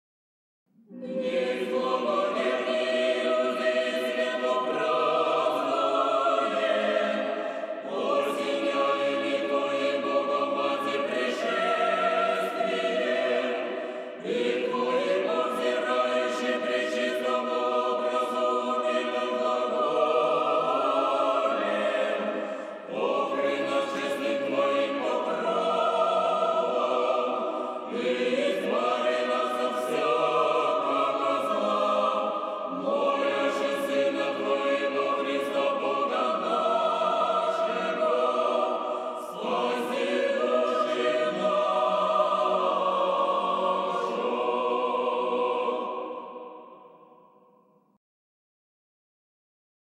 Православни песнопения